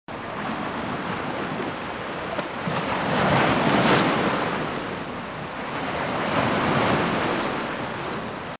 playa
Sonido FX 29 de 42
playa.mp3